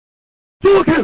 fatal1gen-hishoken.mp3